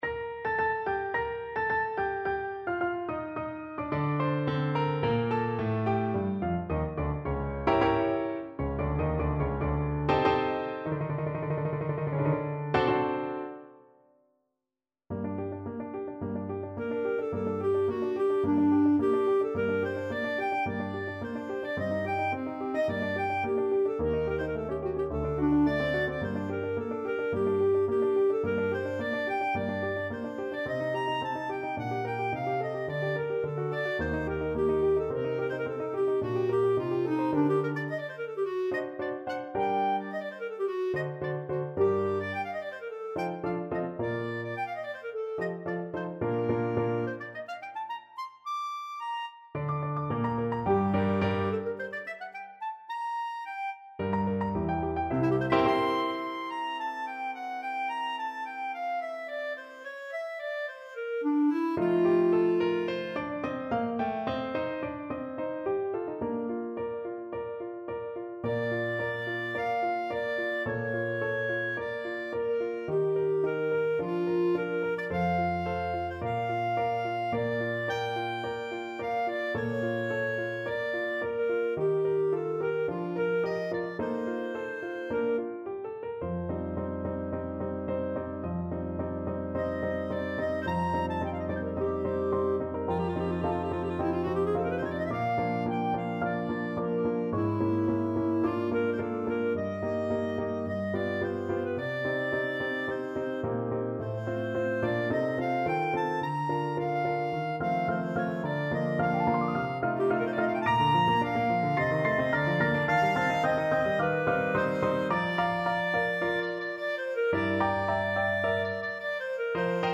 Clarinet version
Allegro Moderato = 108 (View more music marked Allegro)
4/4 (View more 4/4 Music)
Classical (View more Classical Clarinet Music)